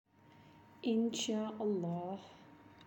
How to Say IN SHAA ALLAH
insha-Allah.aac